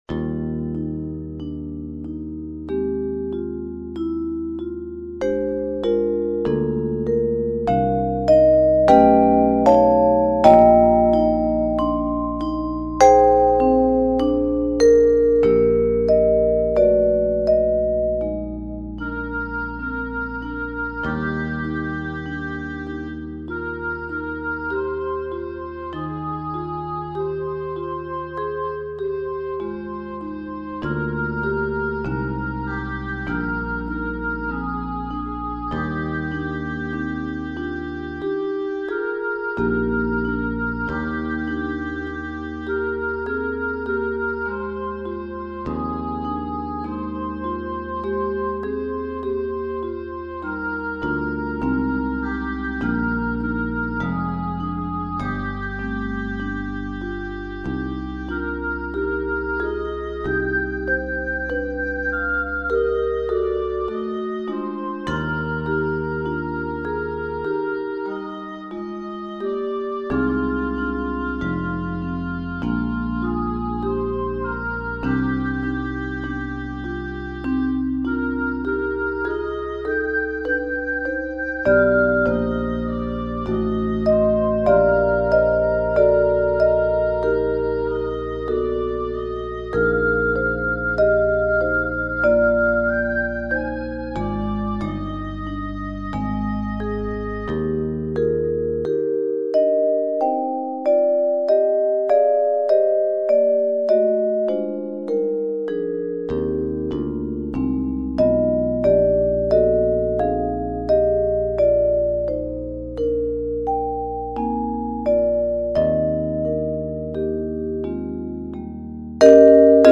Keys of Eb Major and Ab Major.